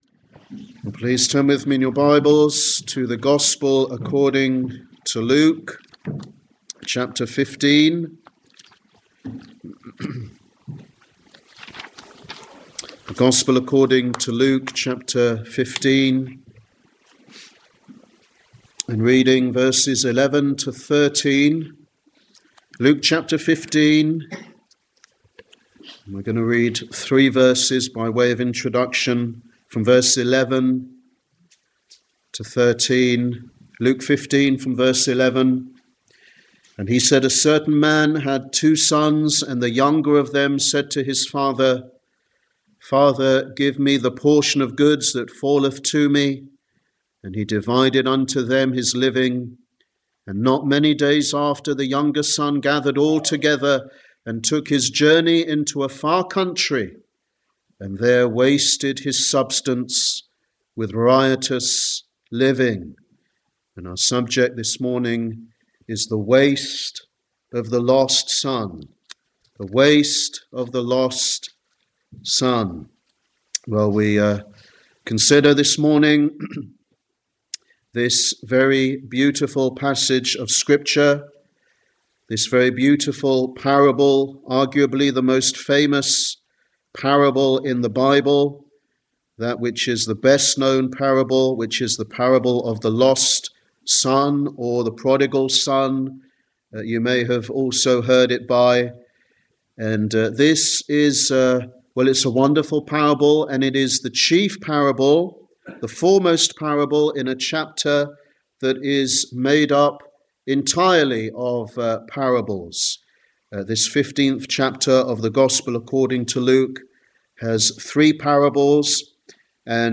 Sunday Evangelistic Service
Sermon